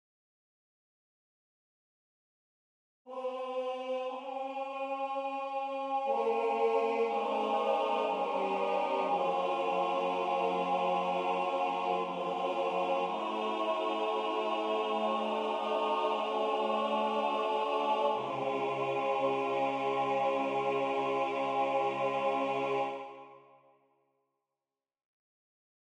Key written in: C# Major
How many parts: 4
Type: Barbershop
All Parts mix: